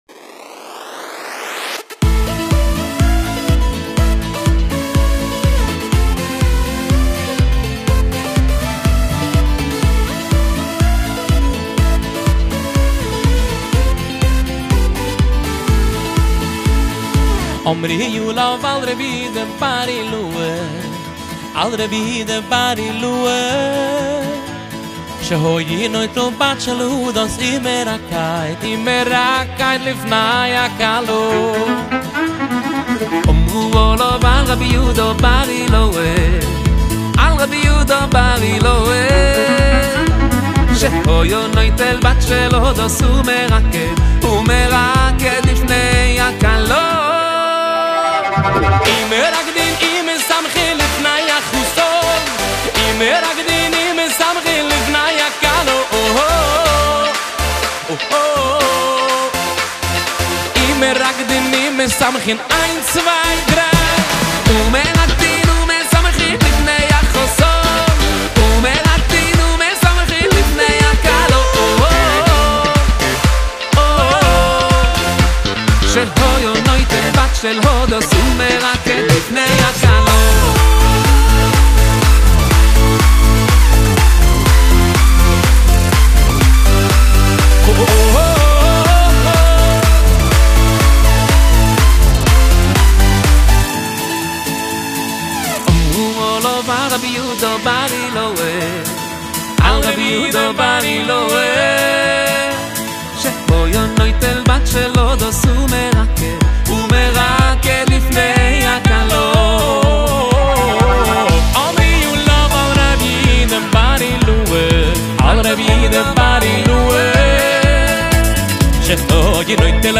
דואט חדש ואנרגטי